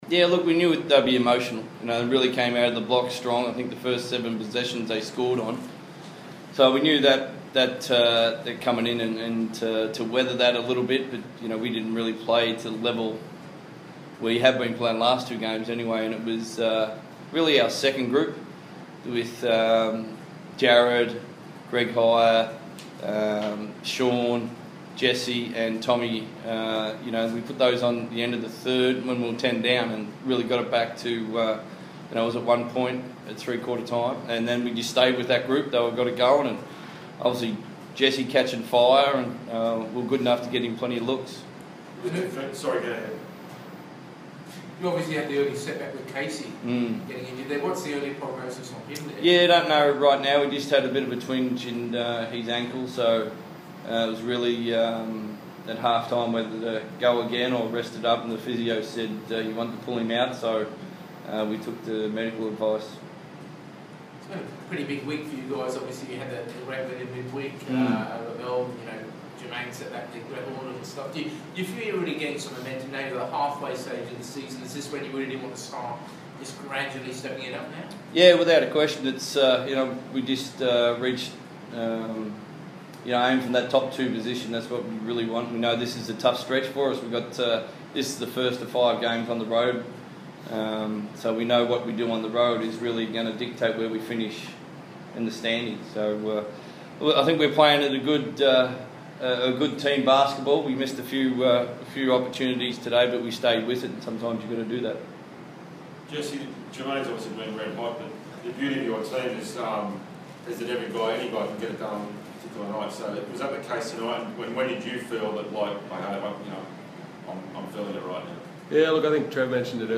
speak to the media following the Wildcats win over the Kings.